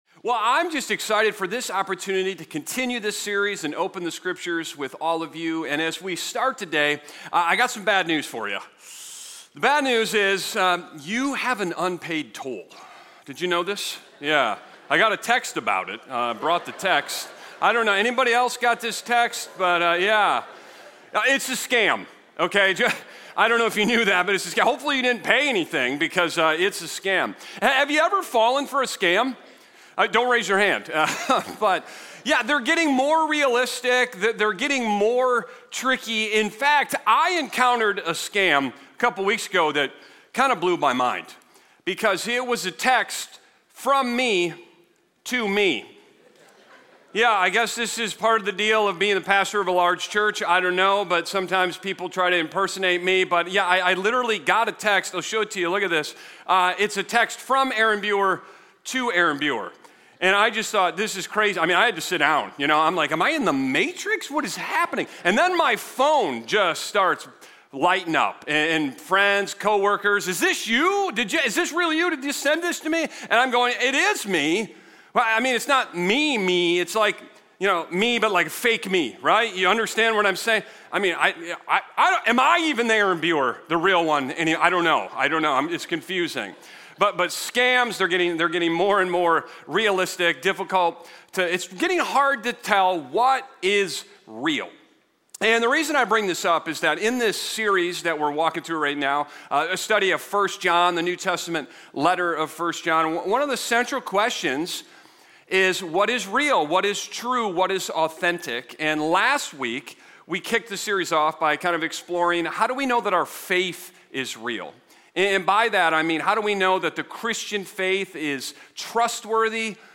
Sermon Discussion